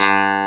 CLAV2HRDG2.wav